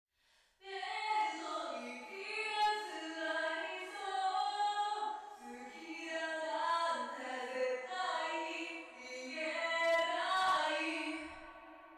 これだけだと物凄い反響していて歌詞など聞き取れません。
♪リバーブのかかったファイル